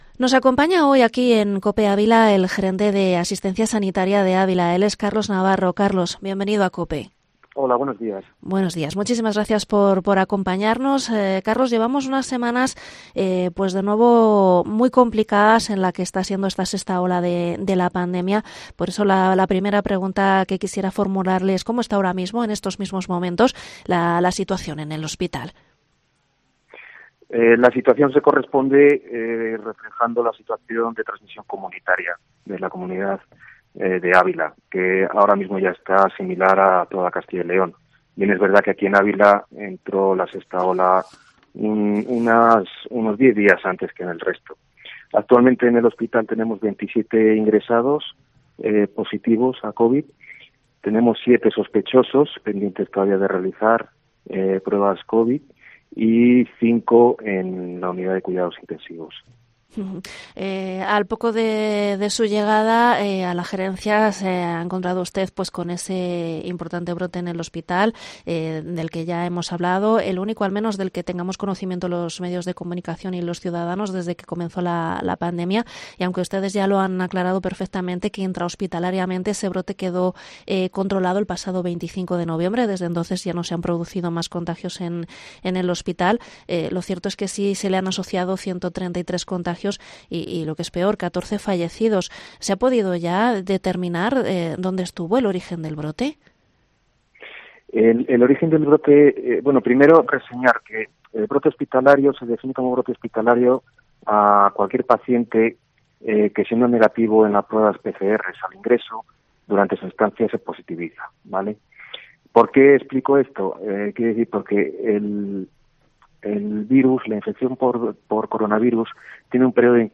Entrevista en Cope Ávila